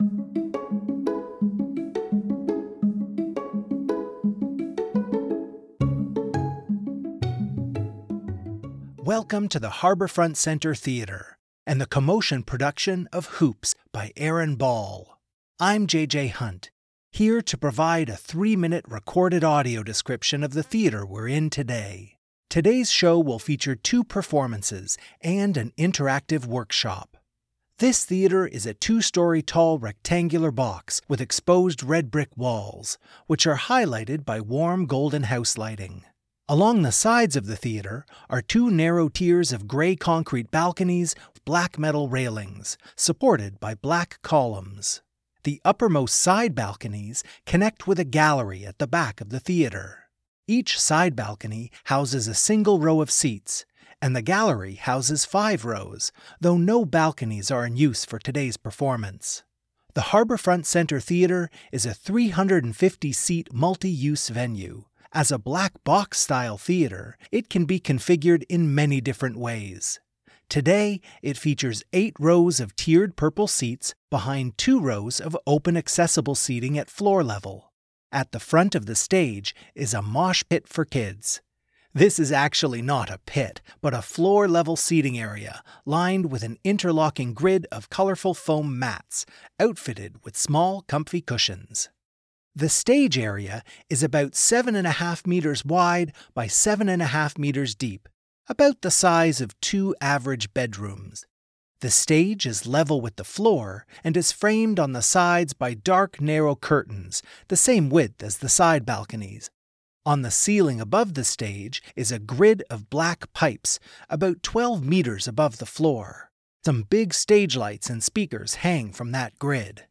Audio Description introductory notes.